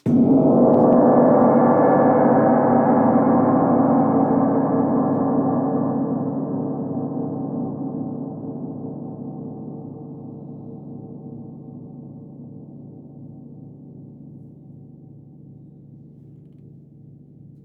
Grandgong_1er_essaie.wav